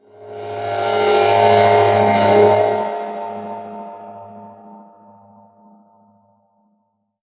VEC3 FX Athmosphere 03.wav